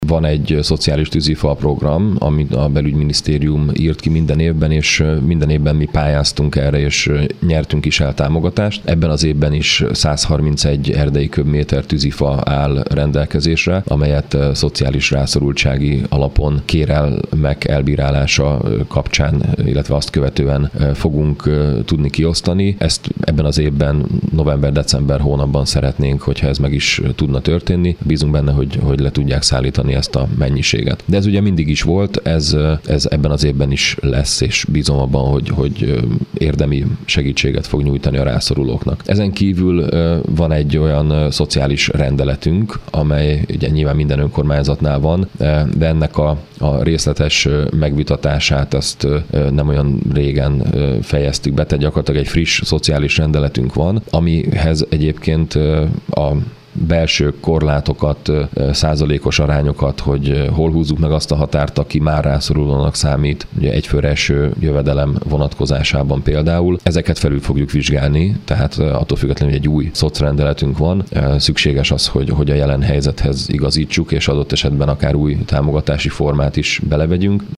Mészáros Sándor polgármester elmondta, ezen kívül új szociális rendeletük is van